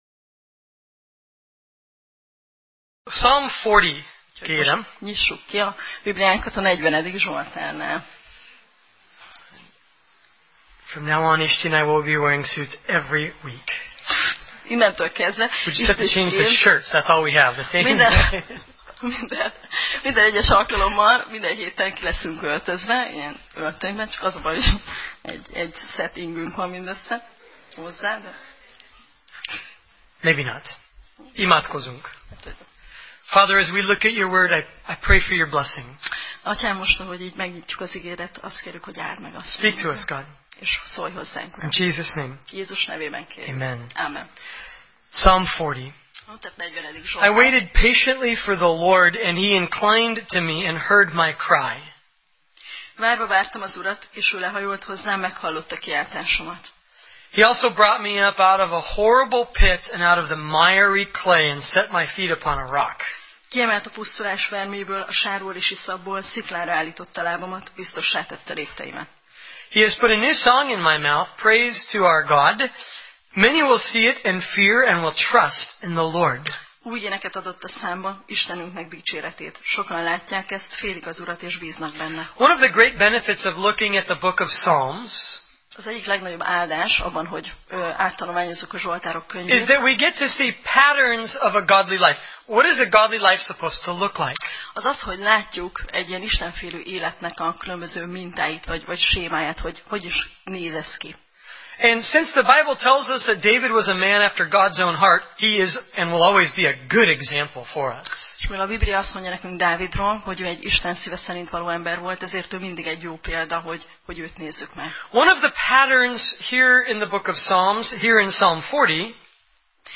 Zsoltárok Passage: Zsoltárok (Psalm) 40:1–6 Alkalom: Szerda Este